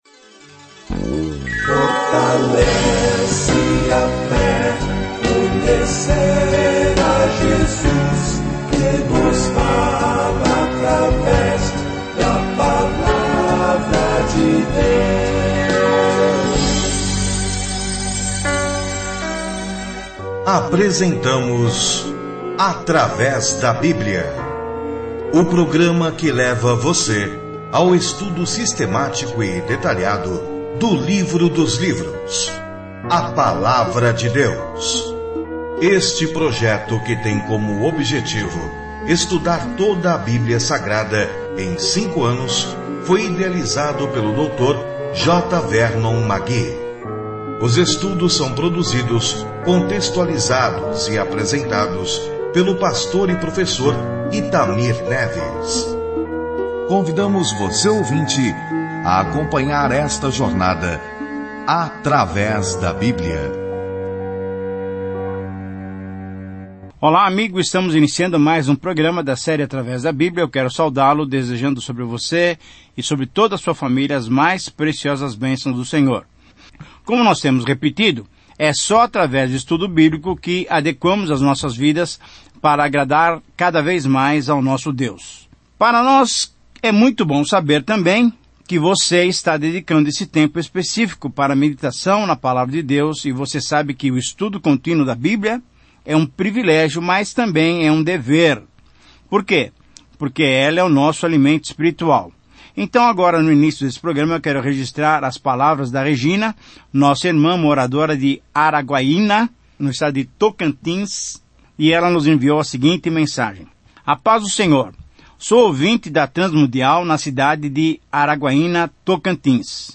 As Escrituras 2Timóteo 1:1-2 Começar esse Plano Dia 2 Sobre este Plano A segunda carta a Timóteo exorta o povo de Deus a defender a palavra de Deus, a guardá-la, pregá-la e, se necessário, sofrer por ela. Viaje diariamente por 2 Timóteo enquanto ouve o estudo em áudio e lê versículos selecionados da palavra de Deus.